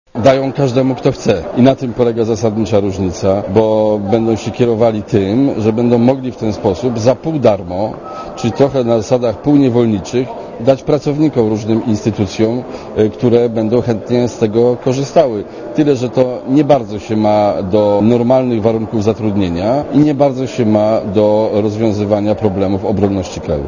Dla Radia Zet mówi Bronisław Komorowski (92 KB)